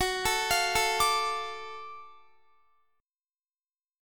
F#mM7#5 Chord
Listen to F#mM7#5 strummed